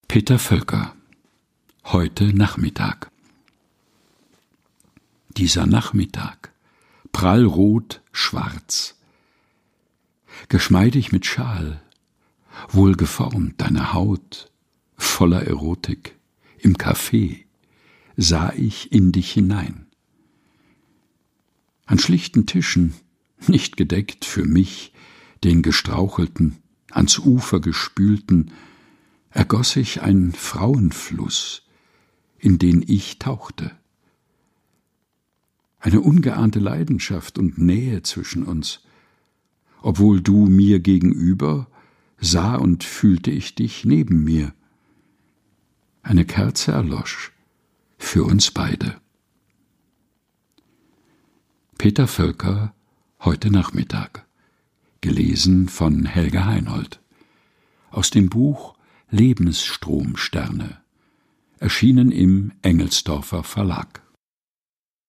Vorgelesen
liest sie in seinem eigens zwischen Bücherregalen eingerichteten, improvisierten Studio ein.